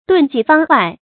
遁跡方外 注音： ㄉㄨㄣˋ ㄐㄧˋ ㄈㄤ ㄨㄞˋ 讀音讀法： 意思解釋： 猶言避世隱居于僧道中。